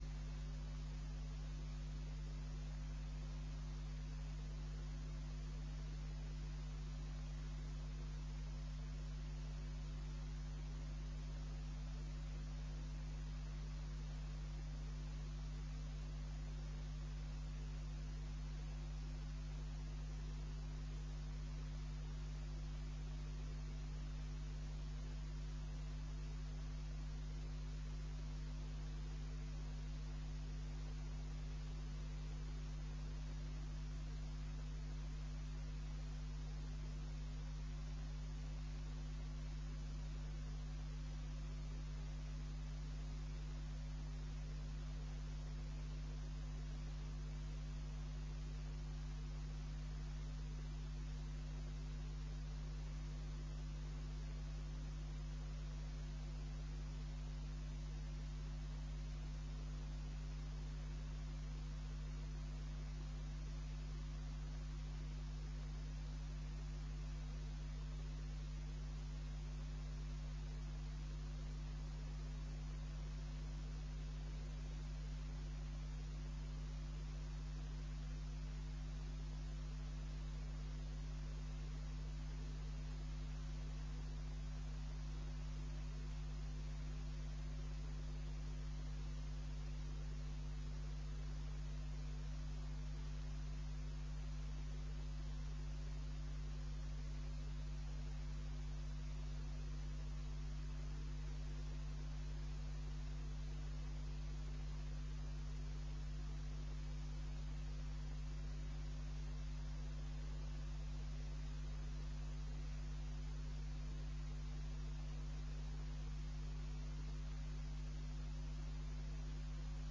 Gemeenteraad 13 oktober 2009 20:00:00, Gemeente Tynaarlo
Download de volledige audio van deze vergadering